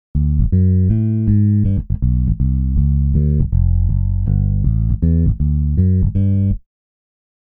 あれを駆使するとスウィング感がばっちりでるのよ。
すごいイブっ！ドラムなくっても
ベースだけでスウィングリズムを感じるよっ！